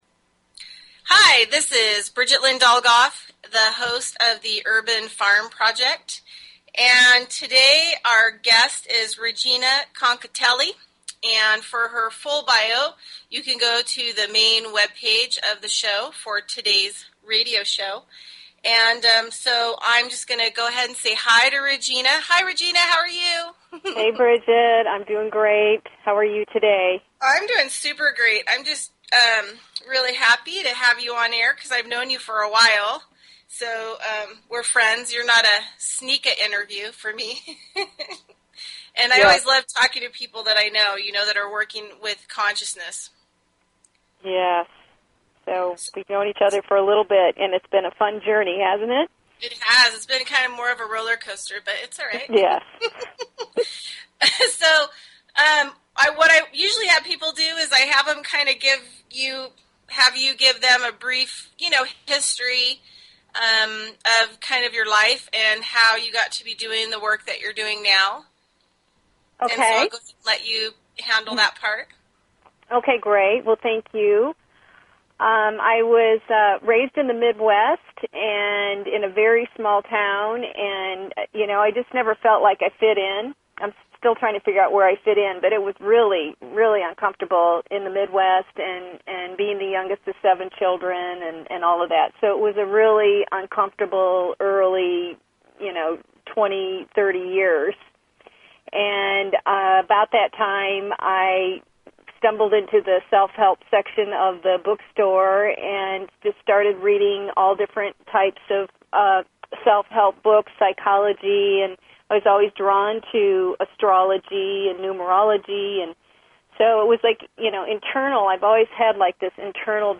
Talk Show Episode, Audio Podcast, The_Urban_Farm_Project and Courtesy of BBS Radio on , show guests , about , categorized as